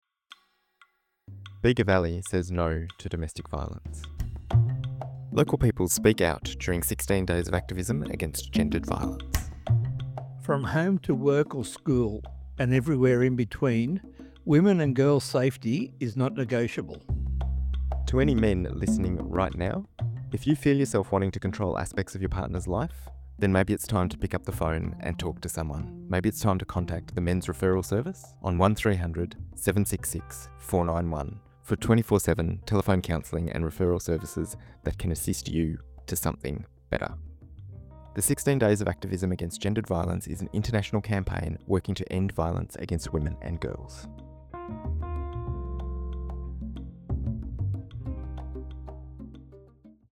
This year, the Bega Valley Domestic Violence & Sexual Assault Committee collected 25 statements from Bega Valley Community members, men and women from all walks of life, calling on all of us to do our part to stop Gender-Based Violence.
As part of this campaign, we collected brief audio statements from local Bega Valley community members to raise awareness about domestic, family, and sexual violence.